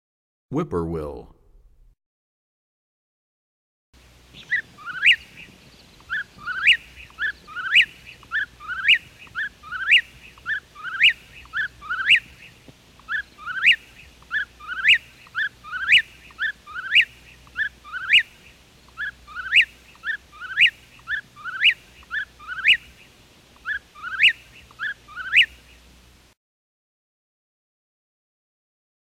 96 Whip-Poor-Will.mp3